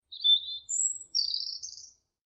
Skog SMS (Fågel)
Djur , sms , Fågelsång